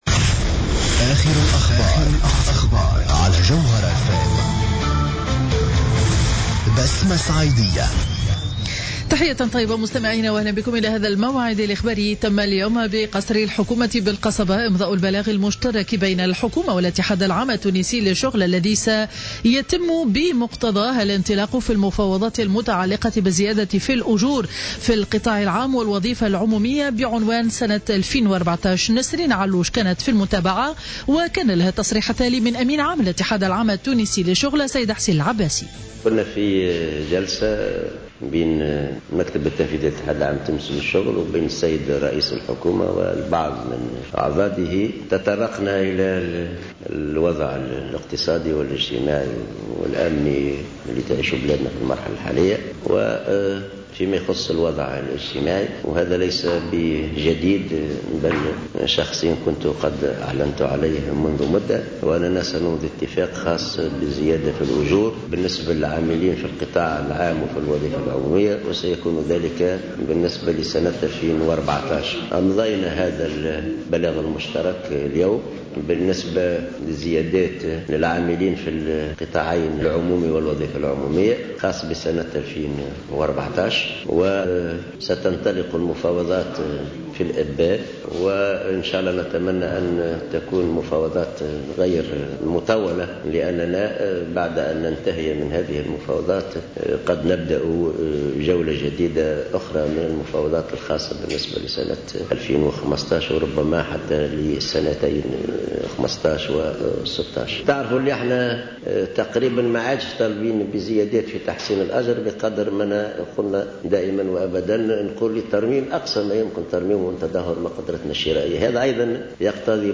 نشرة أخبار منتصف النهار ليوم الإثنين 23 فيفري 2015